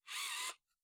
robotics2.wav